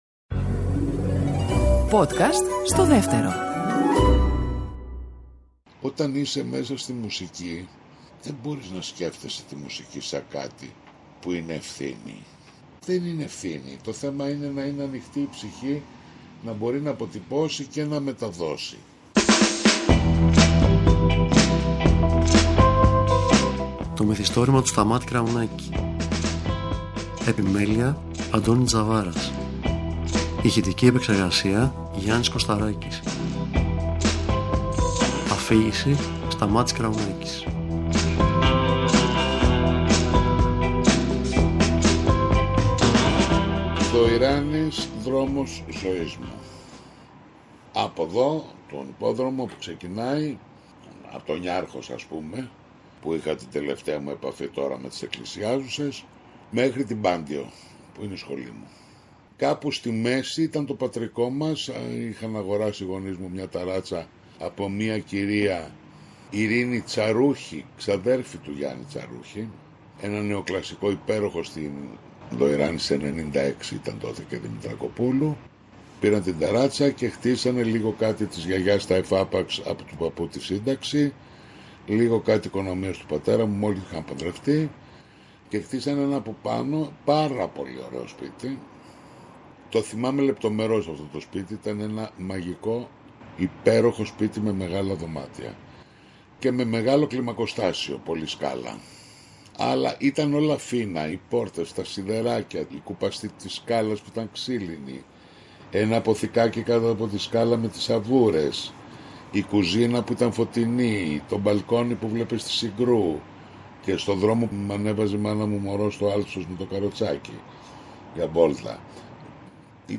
Ο Σταμάτης Κραουνάκης, σε ένα χορταστικό «χειροποίητο» μυθιστόρημα που ηχογραφήθηκε στο σπίτι του, αφηγείται ιστορίες για τα τραγούδια του, ιστορίες από τη ζωή του αλλά και ιστορίες βγαλμένες από τη ζωή εκεί έξω.